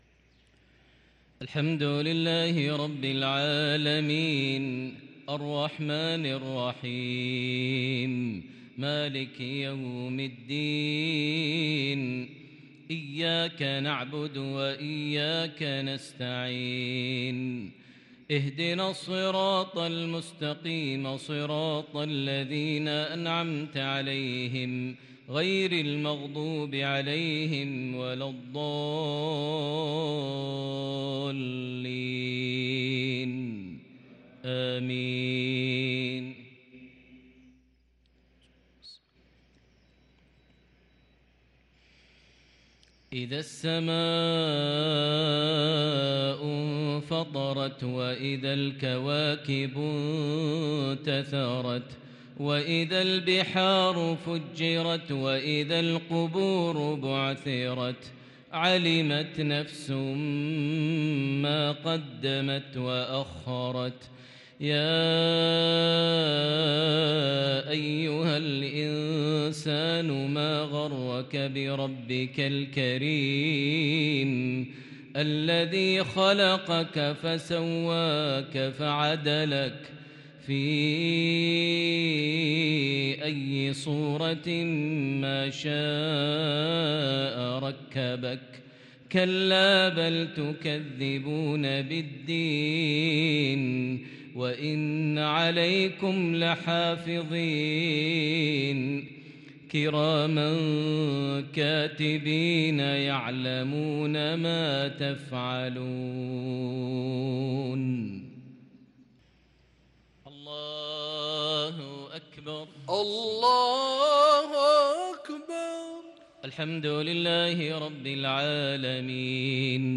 صلاة المغرب للقارئ ماهر المعيقلي 23 ربيع الأول 1444 هـ